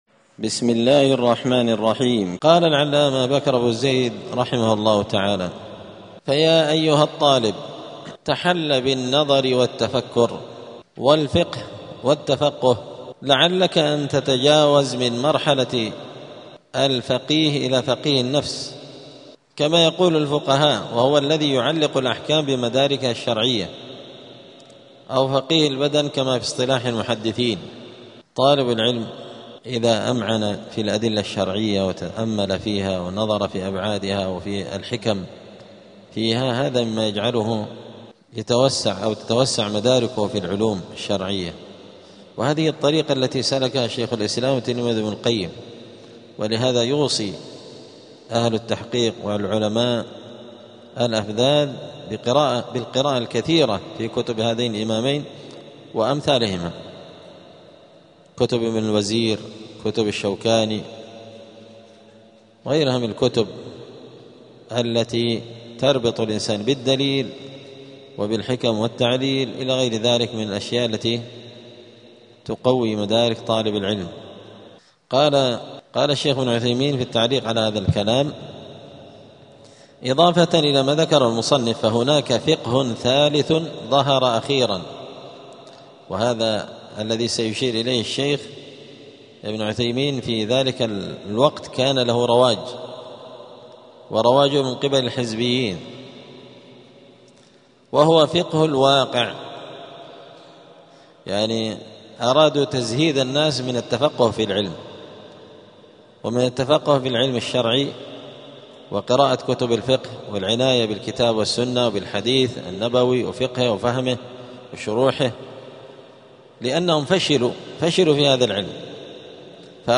*الدرس الخامس والستون (65) فصل آداب الطالب في حياته العلمية {التفقه بتخريج الفروع على الأصول}.*
دار الحديث السلفية بمسجد الفرقان قشن المهرة اليمن